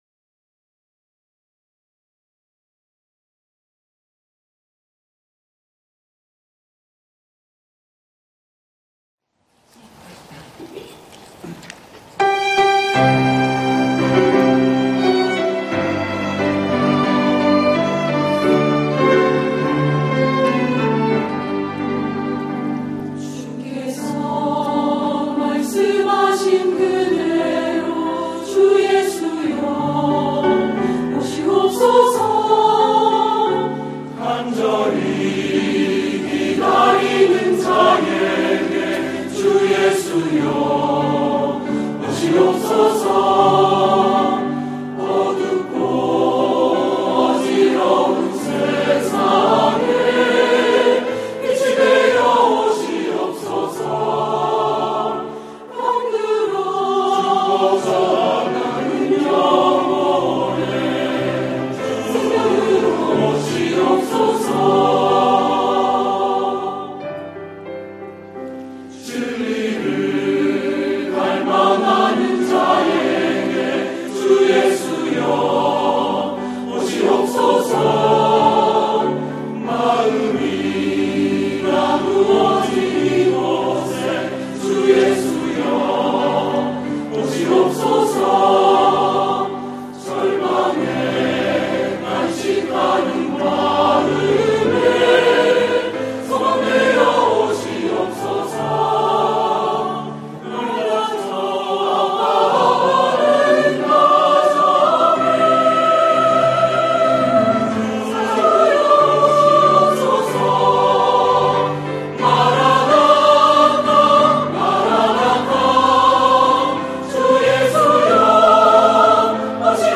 주 예수여 오시옵소서 > 찬양영상